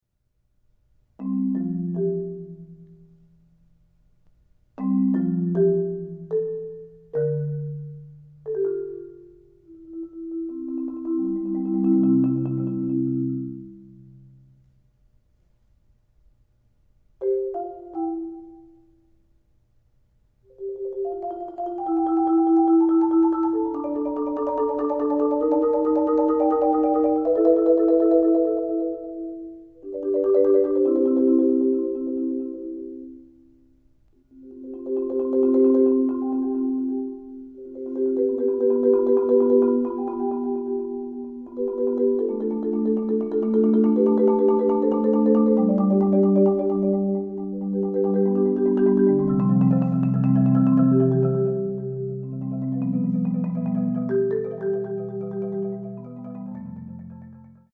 Genre: Marimba (4-mallet)